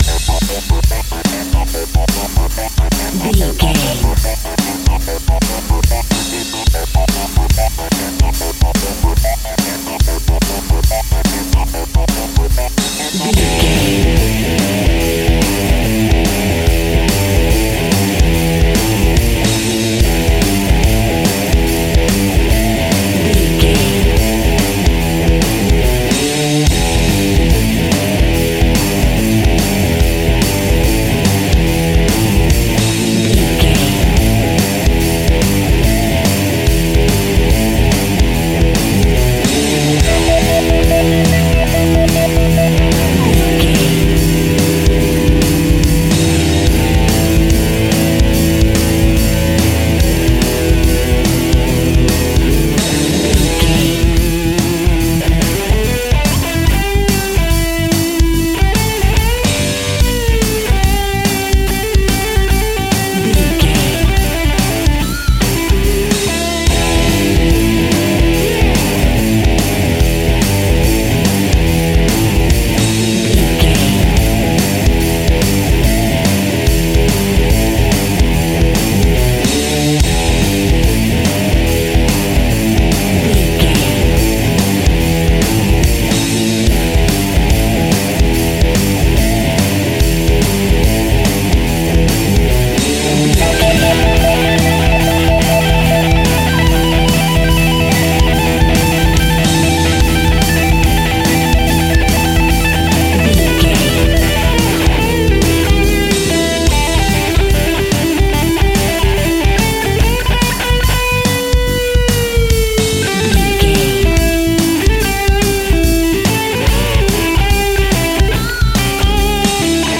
Epic / Action
Aeolian/Minor
heavy metal
blues rock
distortion
instrumentals
rock guitars
Rock Bass
Rock Drums
heavy drums
distorted guitars
hammond organ